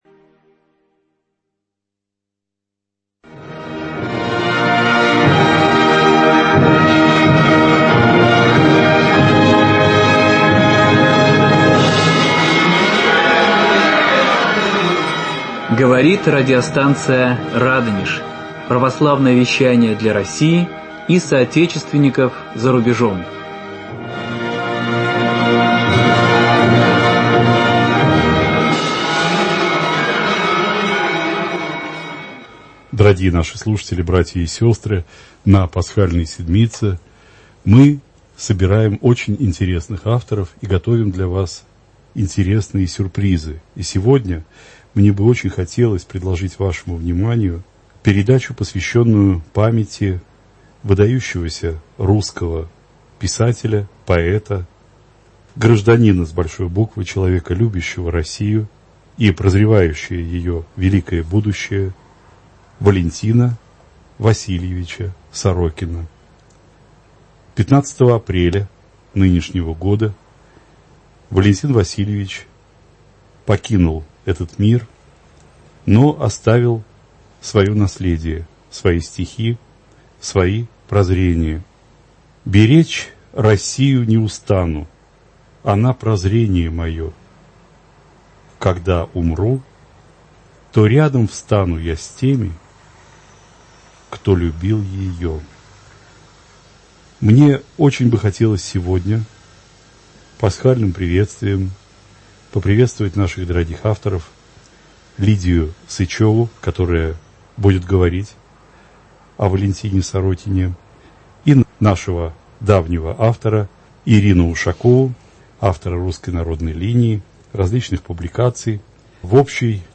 В воскресенье, 11 мая, в 23-00 слушайте поэтическую передачу, посвященную памяти выдающегося русского поэта и писателя Валентина Васильевича Сорокина.